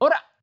戦闘 掛け声 気合い ボイス 声素材 – Battle Cries Voice